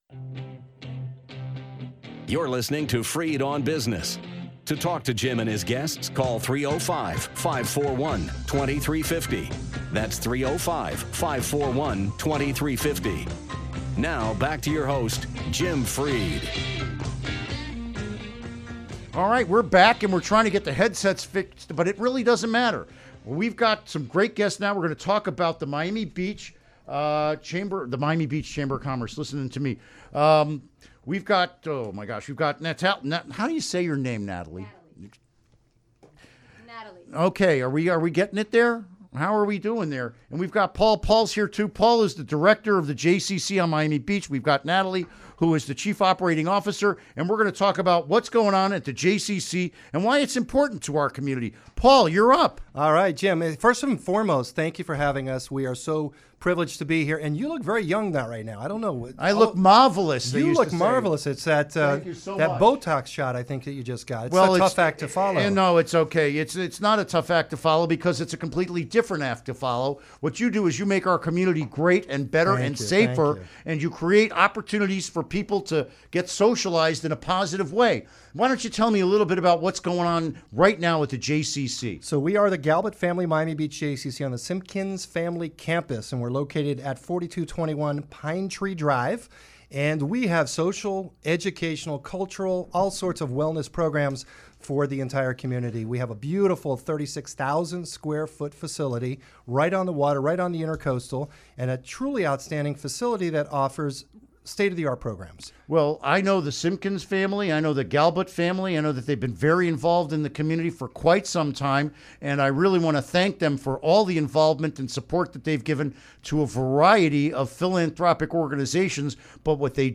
Interview Segment Download Now!